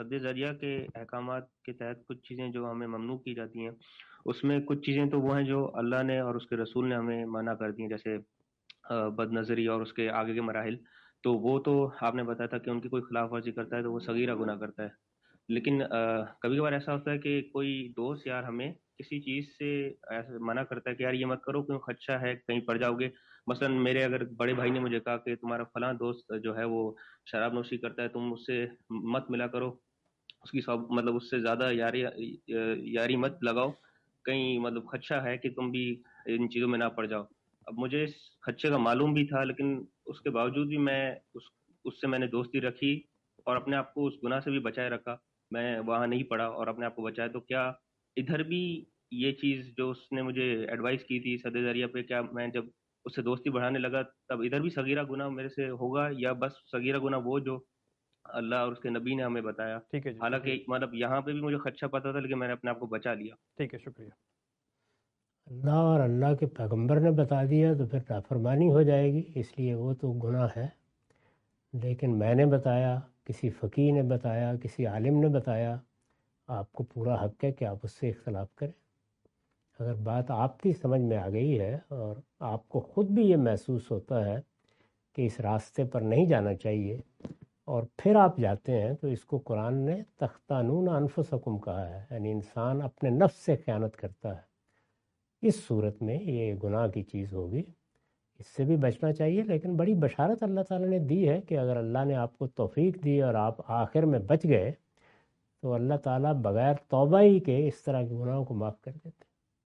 In this video, Mr Ghamidi answer the question about "Will it be a sin to violate the commands through "sad e zriea"?.
اس ویڈیو میں جناب جاوید احمد صاحب غامدی "کیا سد ذریعہ کے احکامات کی خلاف ورزی پر گناہ ہوگا؟" سے متعلق سوال کا جواب دے رہے ہیں۔